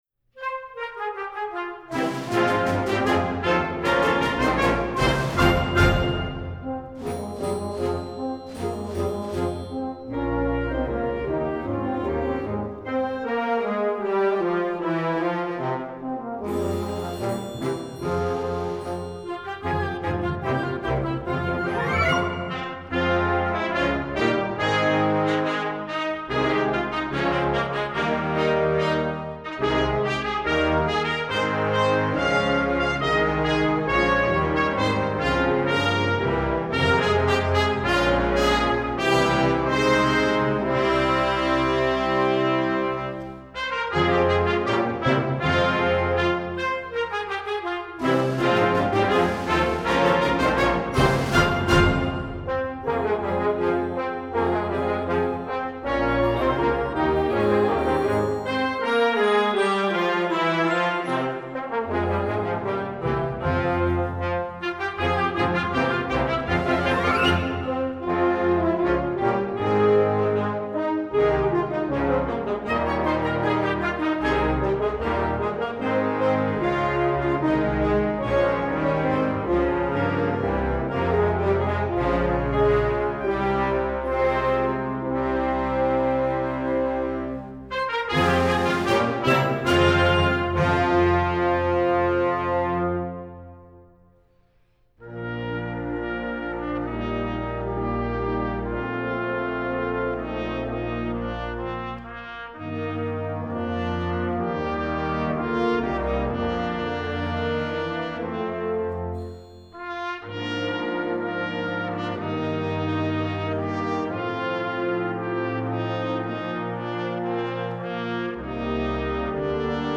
Gattung: Konzertwerk
7:58 Minuten Besetzung: Blasorchester PDF